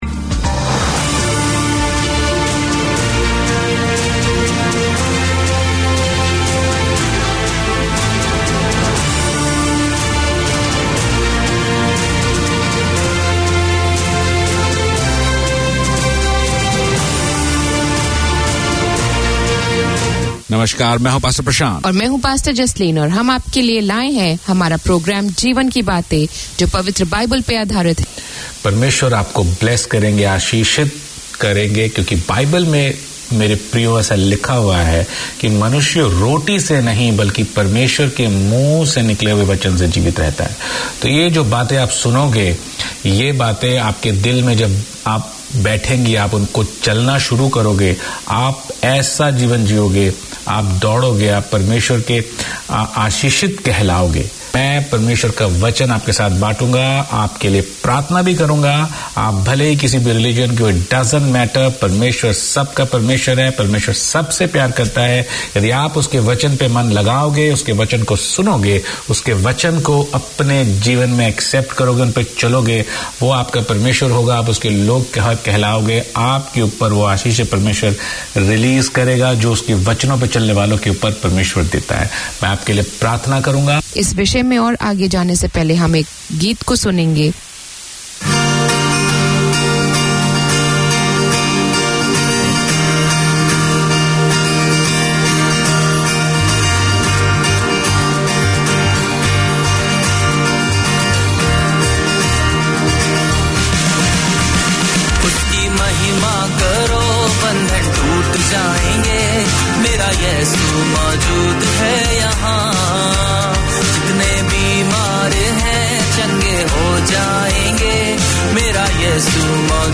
Community Access Radio in your language - available for download five minutes after broadcast.
The programme showcases the history, traditions and festivals of India and Fiji through storytelling and music, including rare Fiji Indian songs.